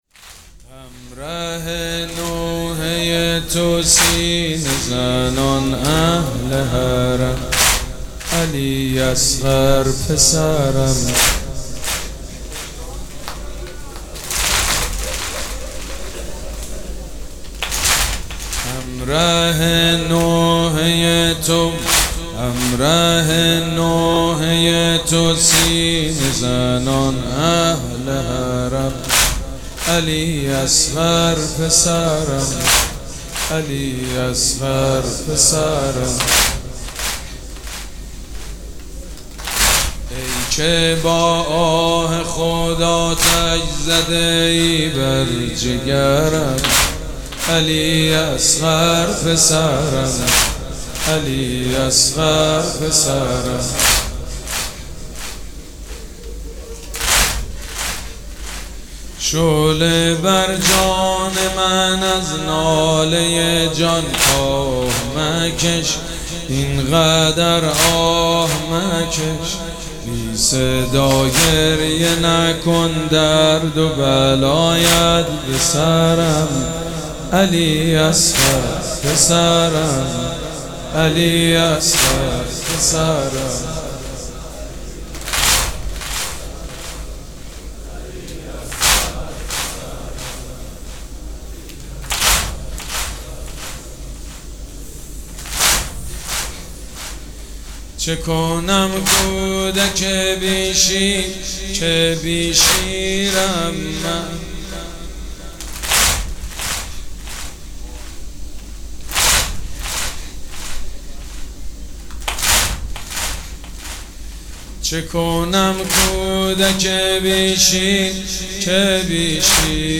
محفل عزاداری شب هفتم محرم
مداحی